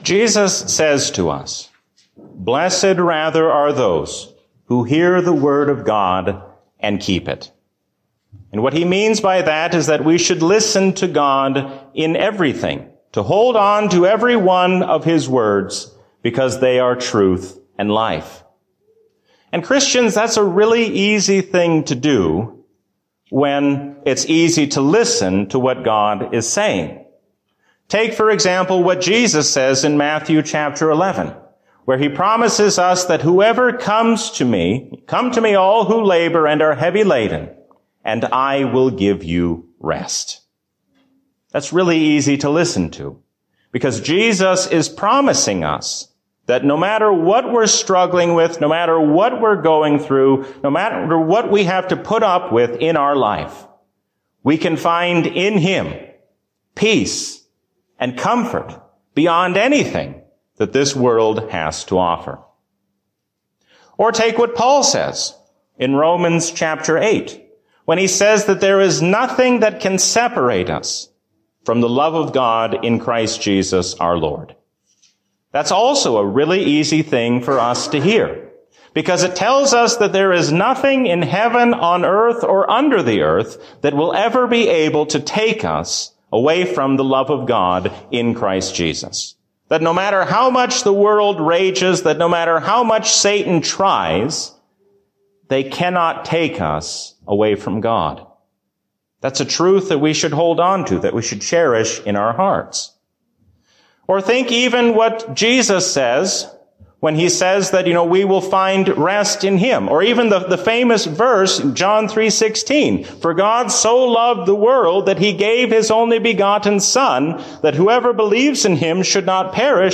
A sermon from the season "Lent 2021." God gives us hope in His Son even when the future seems uncertain.